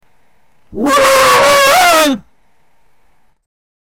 Behavior Sound Effects - Free AI Generator & Downloads
say-bully-high-pitch-grow-yi52qyaf.wav